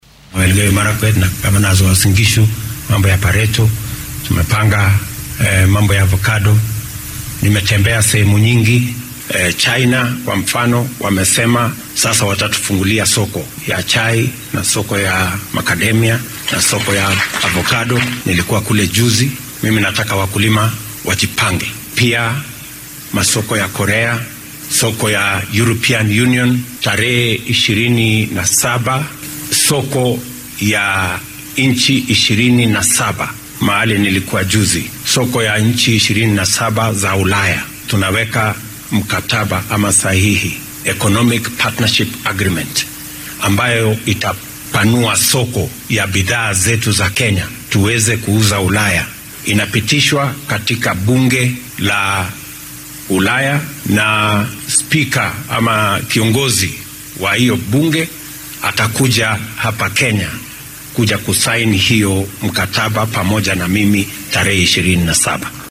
Arrintan ayuu sheegay xilli uu maanta ka qayb galay sanad guuradii 40-aad ee ka soo wareegatay aasaaskii kaniisadda ACK laanteeda magaalada Eldoret ee ismaamulka Uasin Gishu.